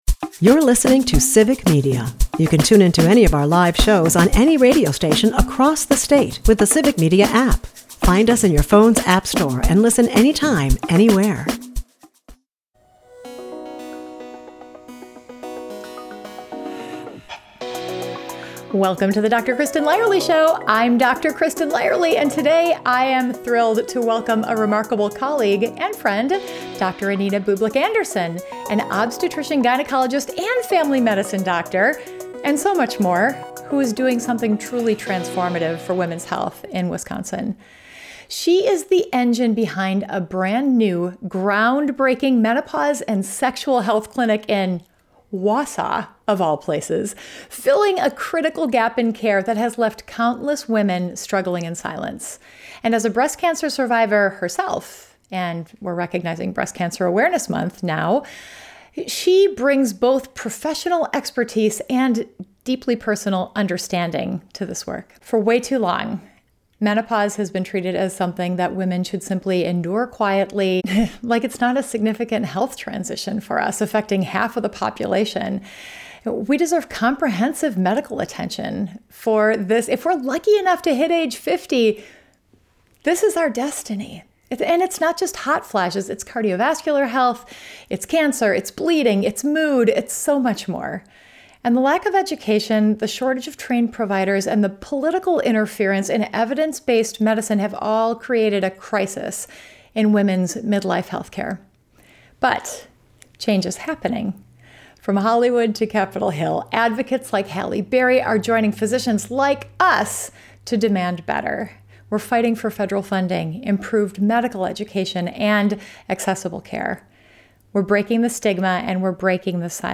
But this conversation is also about hope and action.